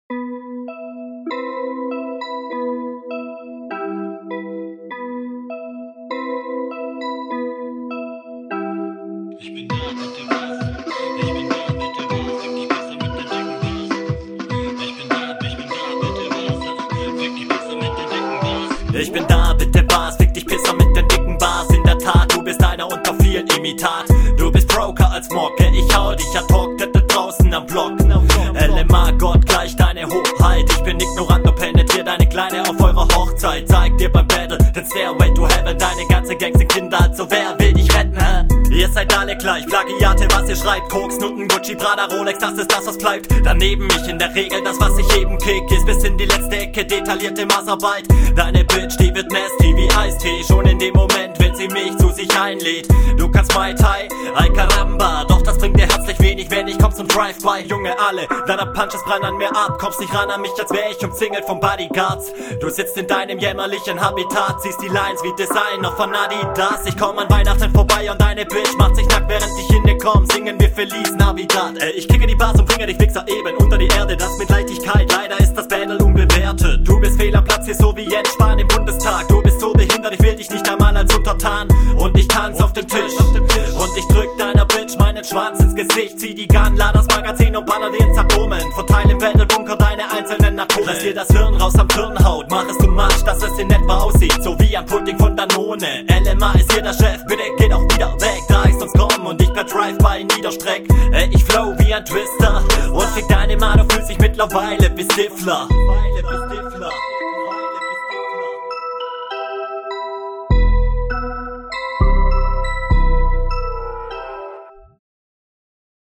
Driveby Runde